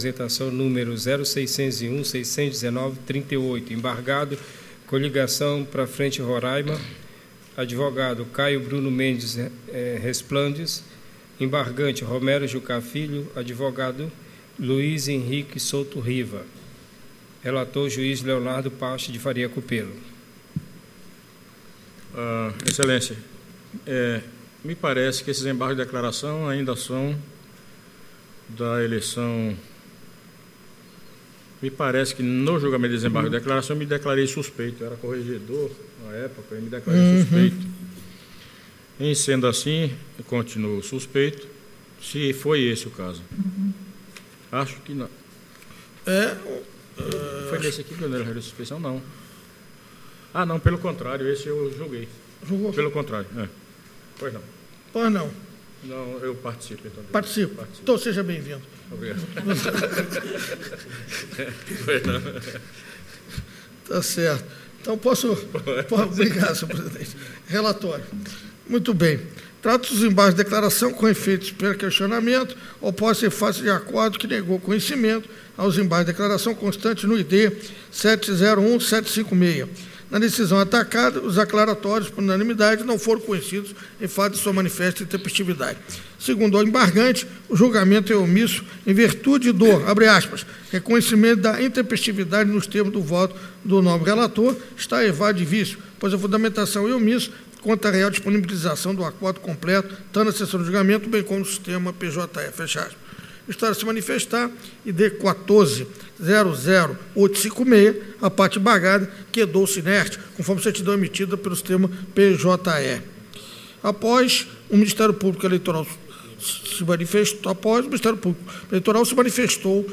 Áudio da 64ª Sessão Ordinária de 02 de setembro de 2019. Parte III